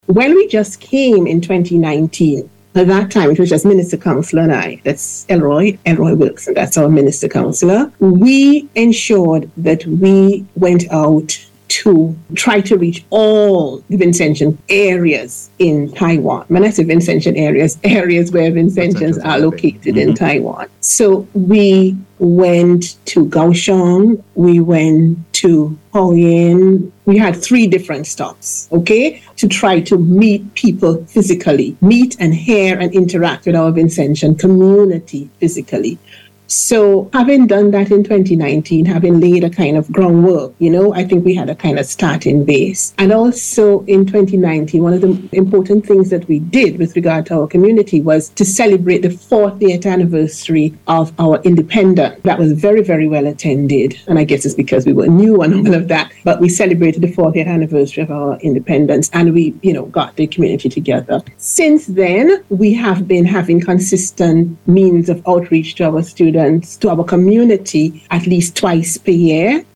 St. Vincent and the Grenadines Ambassador to Taiwan Her Excellency Andrea Bowman commended the organization on Tuesday while speaking on NBC Radio’s “Talk Yuh Talk” Programme.